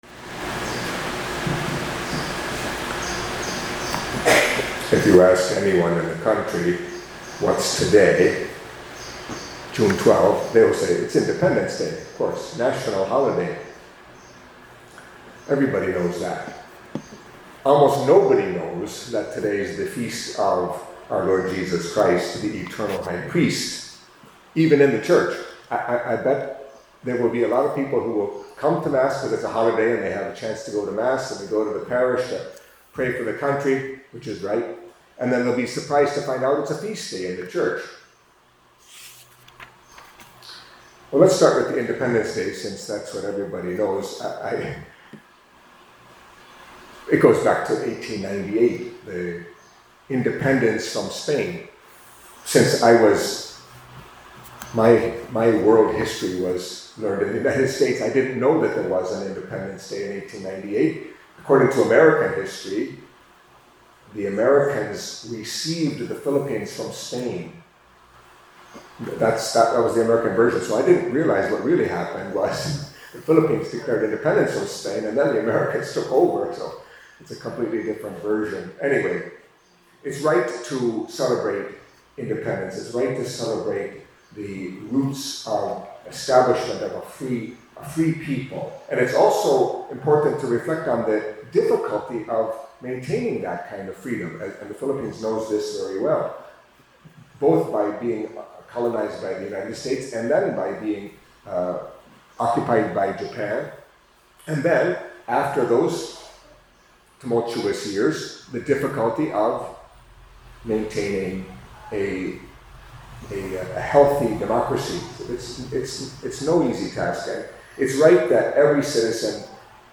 Catholic Mass homily for Feast of Our Lord Jesus Christ, the Eternal High Priest